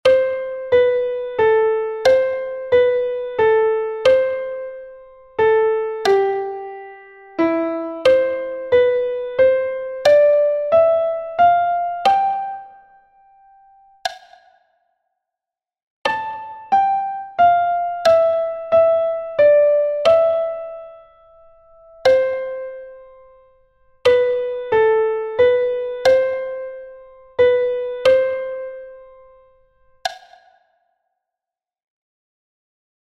Exercise 1: 6/8 time signature.
You have also a metronome sound with the melody in the sound file in this first exercise to help you better understand the time signature.